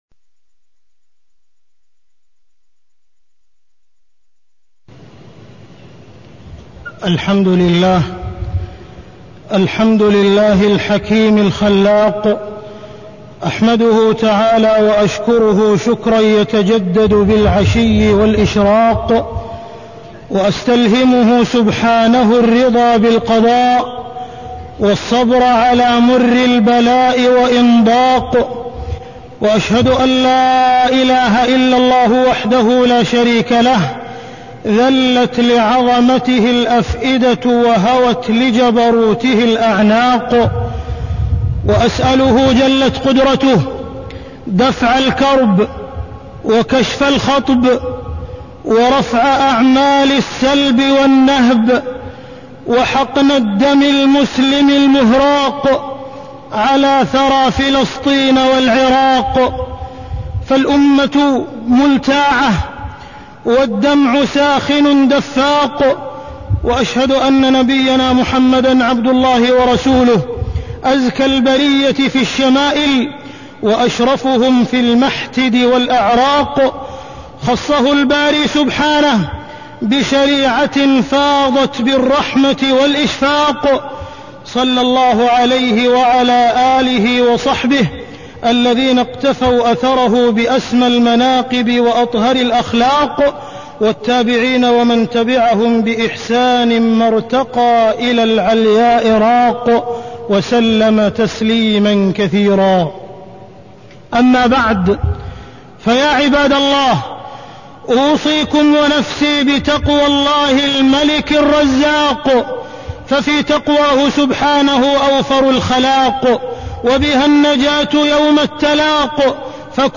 تاريخ النشر ٩ صفر ١٤٢٤ هـ المكان: المسجد الحرام الشيخ: معالي الشيخ أ.د. عبدالرحمن بن عبدالعزيز السديس معالي الشيخ أ.د. عبدالرحمن بن عبدالعزيز السديس فقه النوازل والأزمات